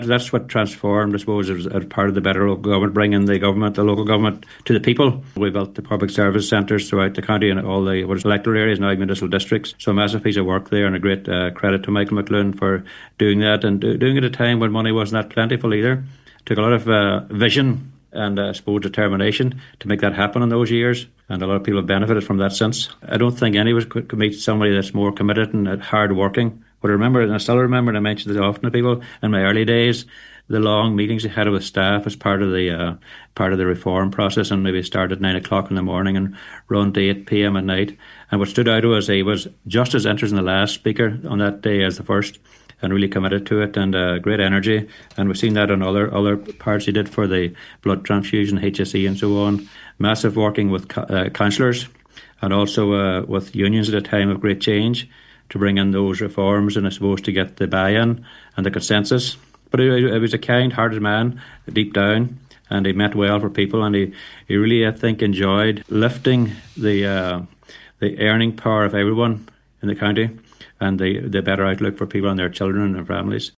On today’s Nine til Noon Show, current Chief Executive John McLaughlin said Michael McLoone’s commitment to local government reform was immense.……………